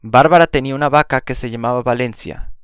Uma característica bastante peculiar do espanhol é que o “b” e o “v” possuem pronúncias semelhantes nessa língua.